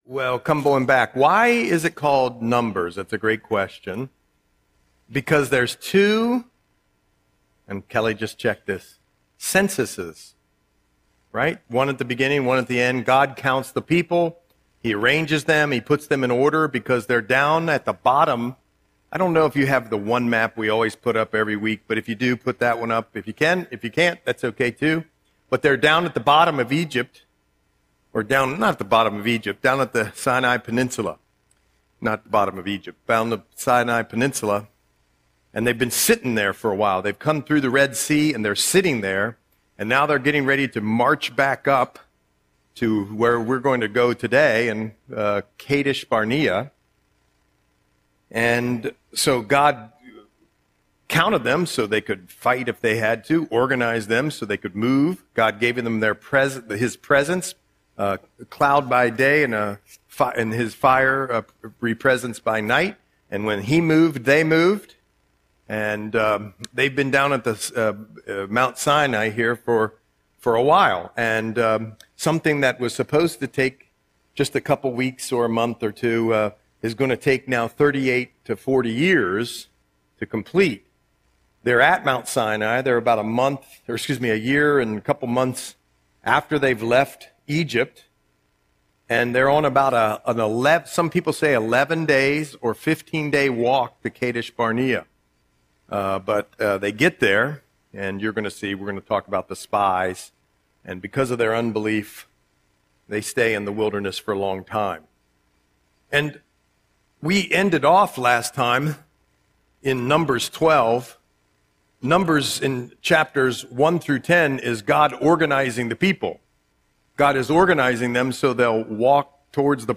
Audio Sermon - March 18, 2026